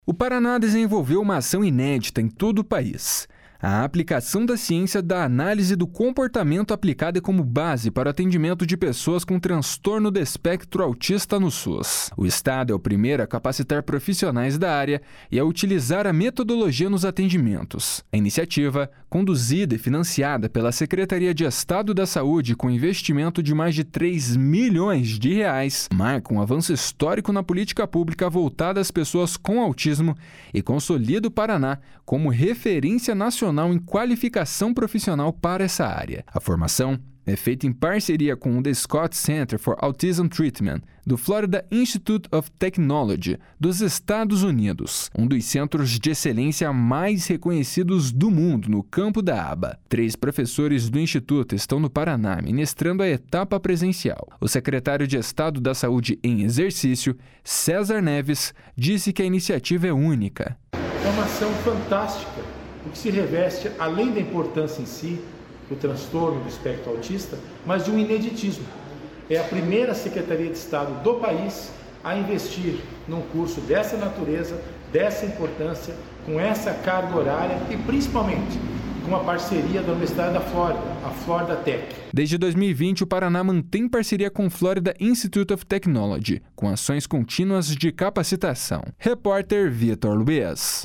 O secretário de Estado da Saúde em exercício, César Neves, disse que a iniciativa é única. // SONORA CÉSAR NEVES //